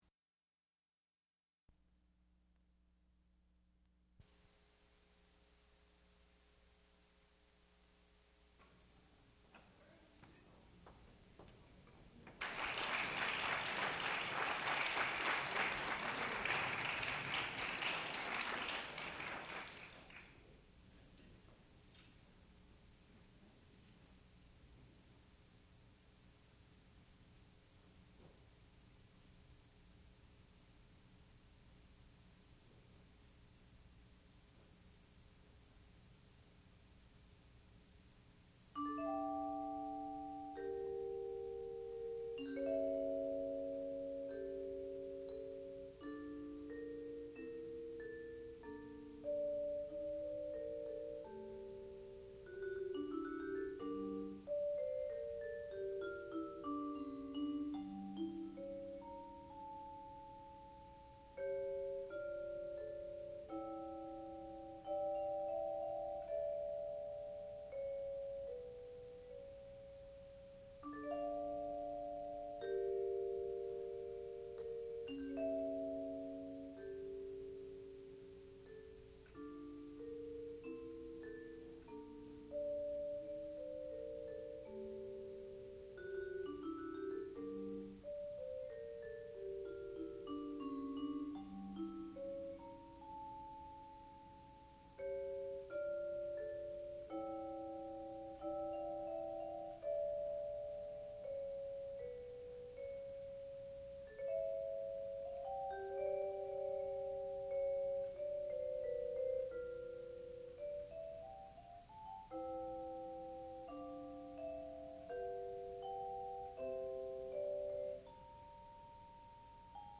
sound recording-musical
classical music
Student Recital
percussion